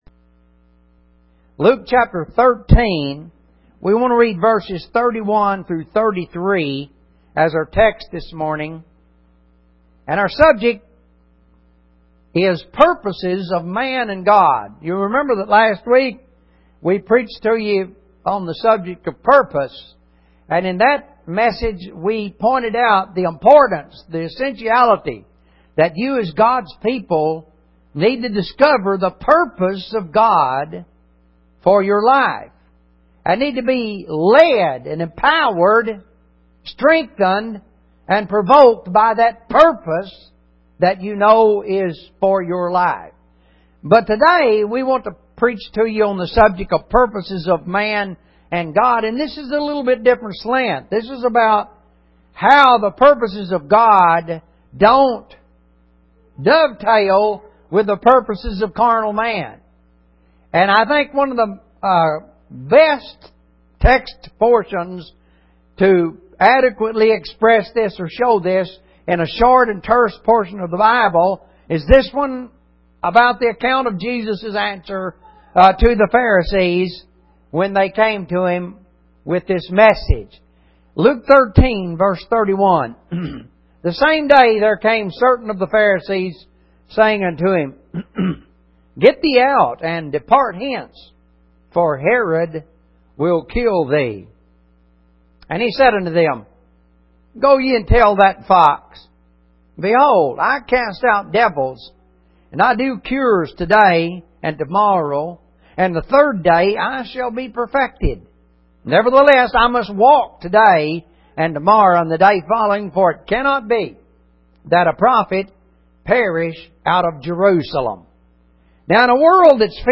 Clicking on an item will open an audio sermon on the subject.